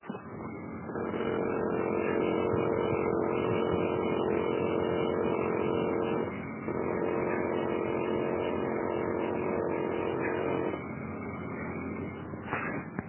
2) Наружный блок сам не трещит но если дотронуться до вентелей наружного бока он начинает трещать
Touched-the-valve-with-his-hand.mp3